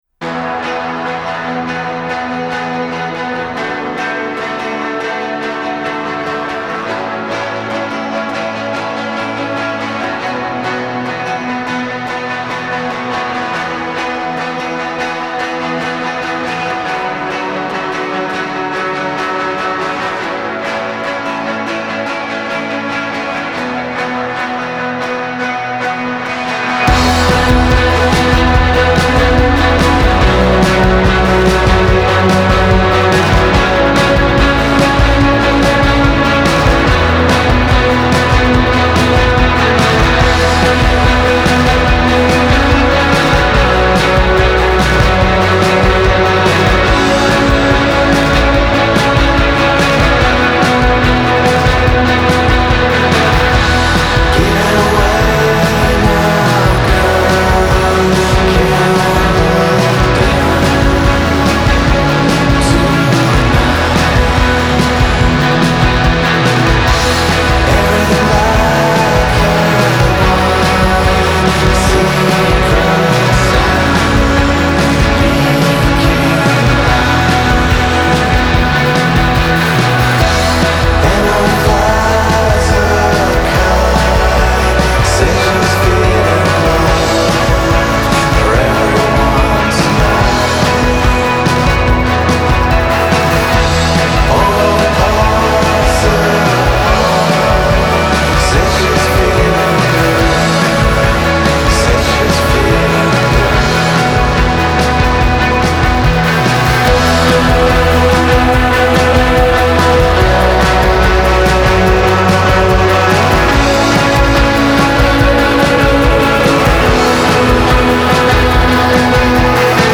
Shoegaze Indie Rock
شوگیزینگ
ایندی راک